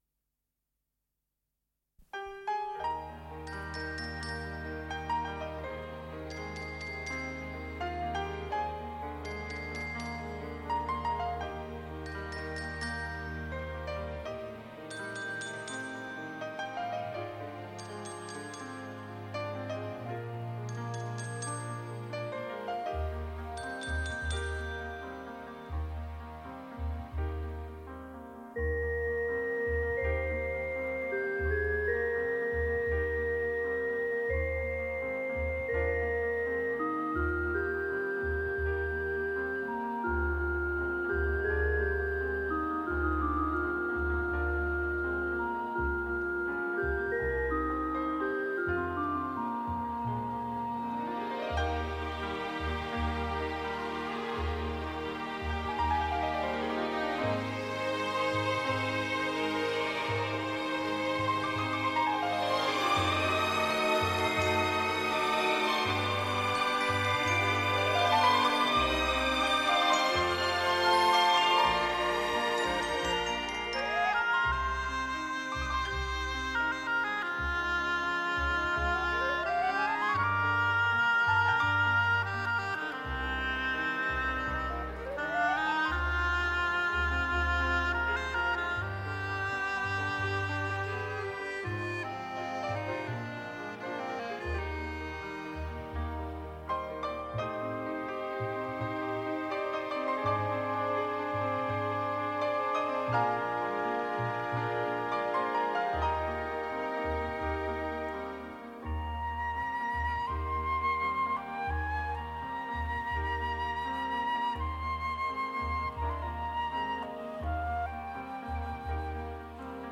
抒情音乐小品
这一个专辑是两口子的“甜点”类器乐，今日听来听不出技巧的过时。